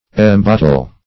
Embattle \Em*bat"tle\, v. i.